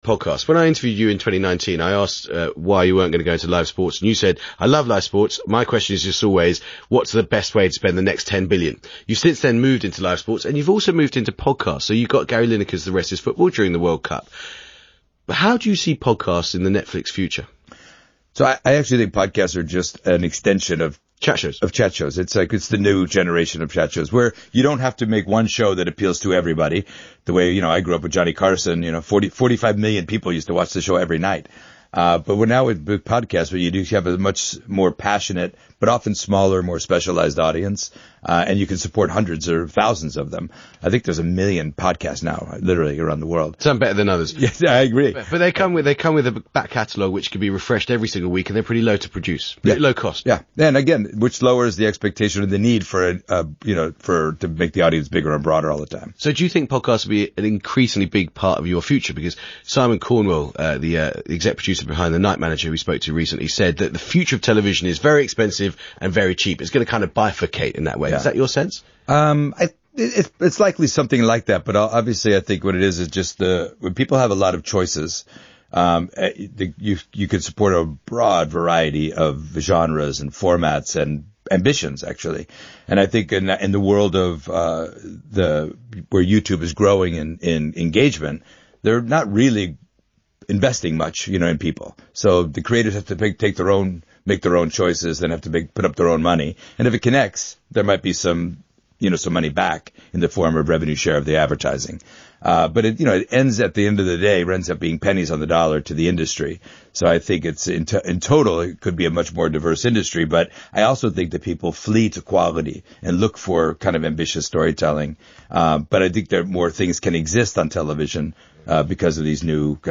• The CEO of Netflix, Ted Sarandos, was on BBC Radio 4’s Today programme this morning.